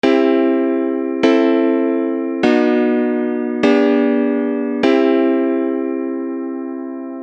The I - vii - VIII - iv° progression in the "Starfish" mode of Porcupine[8]
I_-_vii_-_VIII_-_iv°.mp3